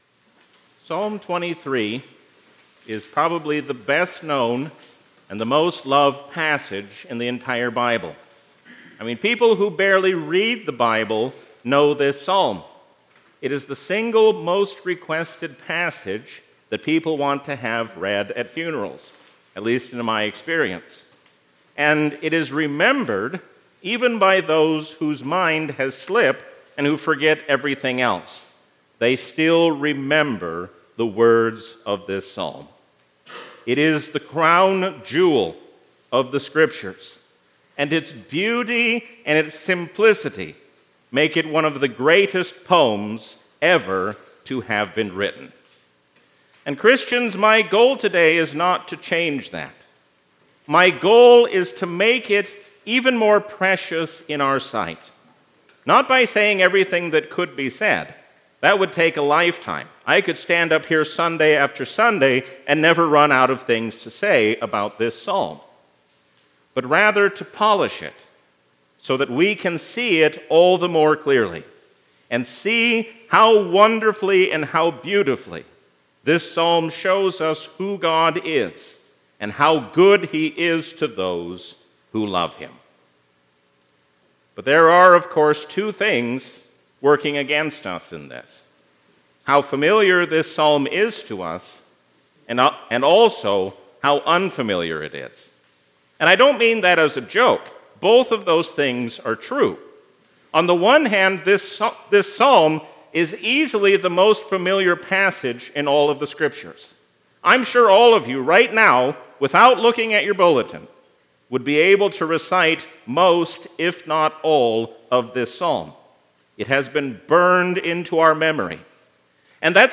A sermon from the season "Easter 2024."